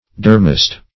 Meaning of durmast. durmast synonyms, pronunciation, spelling and more from Free Dictionary.